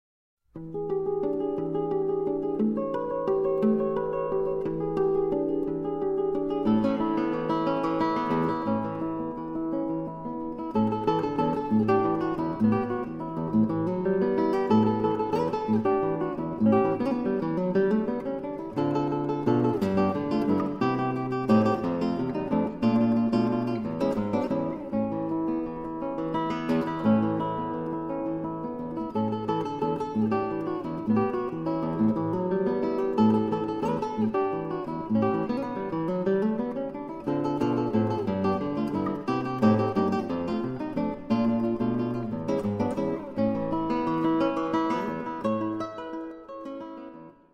SOLO GUITAR WORKS